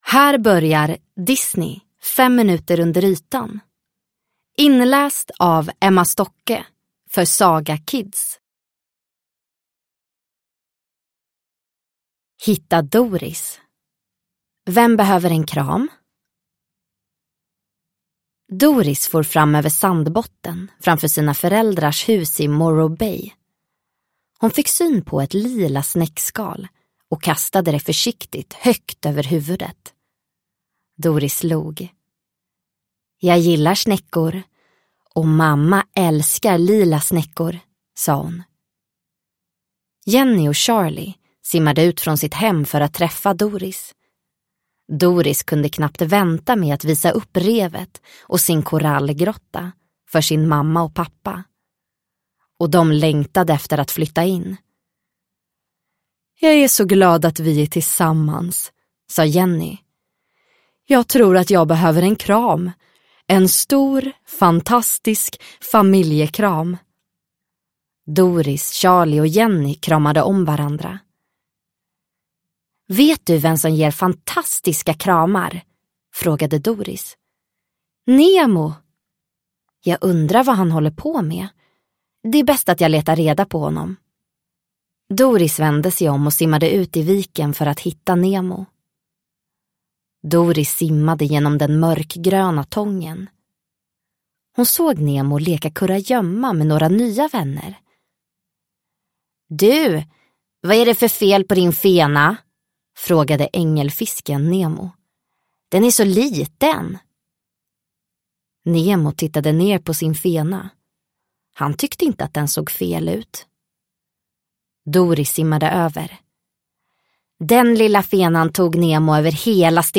Fem minuter under ytan – Ljudbok